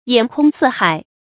眼空四海 注音： ㄧㄢˇ ㄎㄨㄙ ㄙㄧˋ ㄏㄞˇ 讀音讀法： 意思解釋： 形容自高自大，什么都看不見。